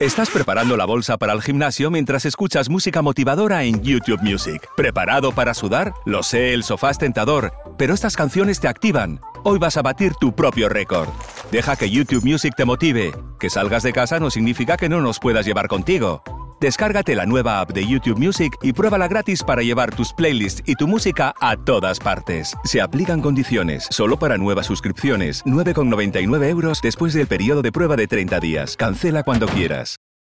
Male
Friendly
Smooth
Warm
Confident
youtube-music-ad-copy.mp3